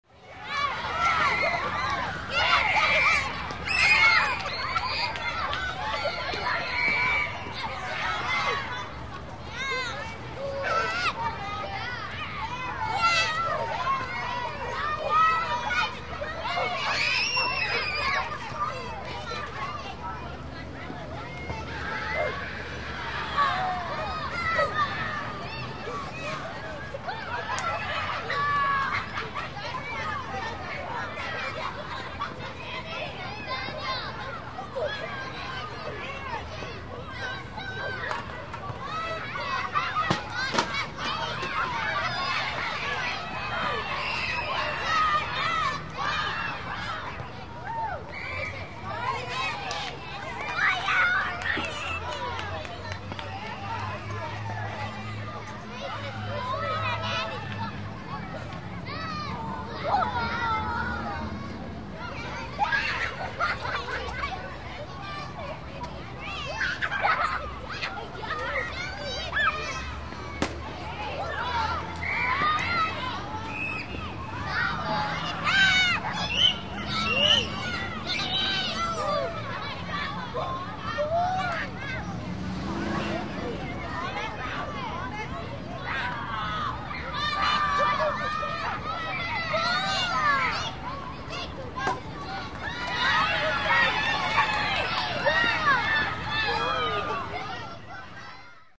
Deti-igraut-na-ylice-vo-dvore-shkoly
• Category: School Break 1301